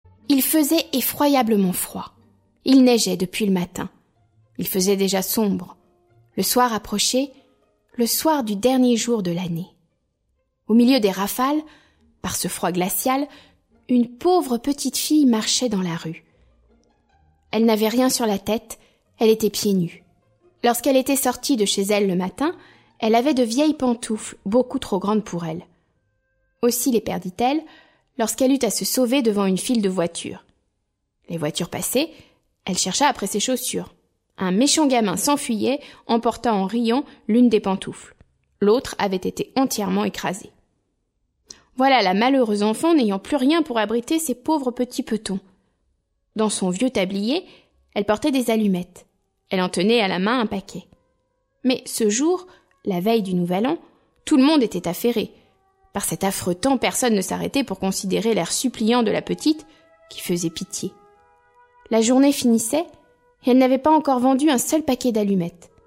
Conte de Hans-Christian Andersen Musique : Delibes (Sylvia Pizzicato) et Debussy (l'après midi du faune)